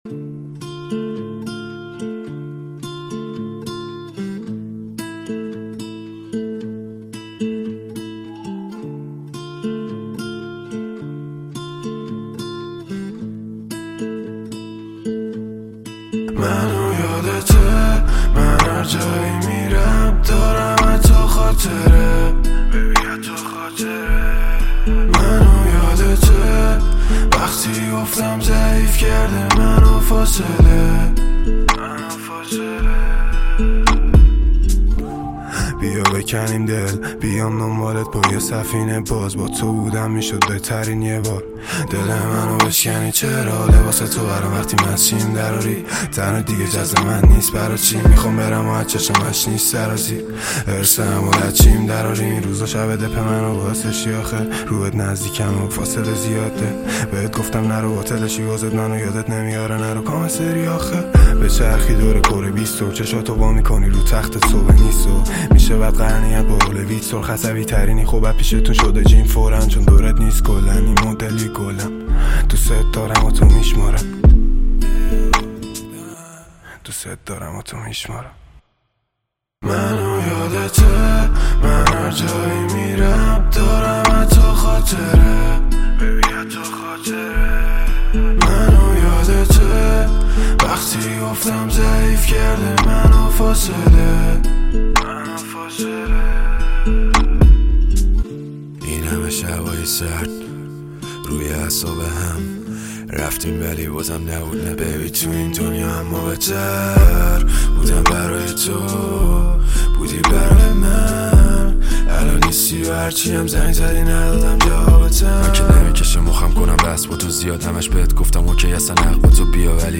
آهنگ احساسی و شنیدنی
این قطعه با ترکیبی از بیت ملایم و اجرای احساسی
رپ فارسی